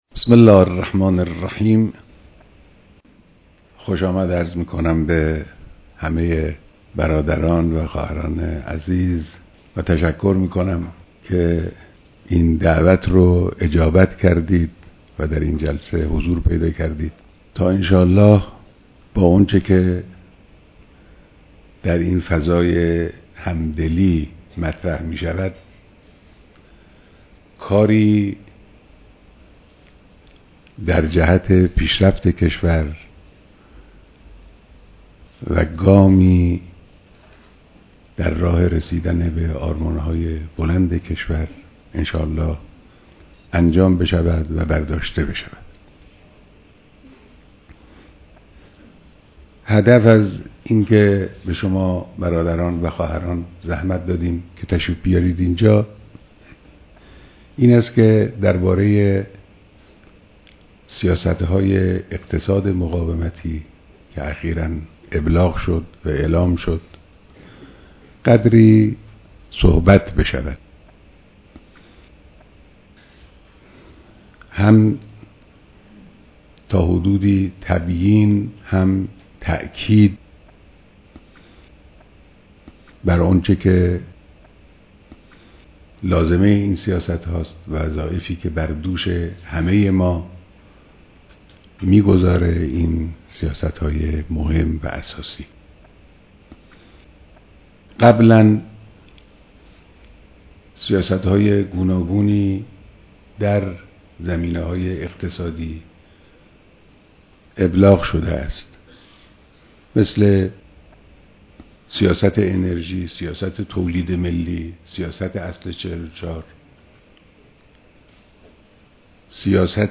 بیانات در دیدار جمعی از مسئولان دستگاه‌های مختلف کشور با موضوع اقتصاد مقاومتی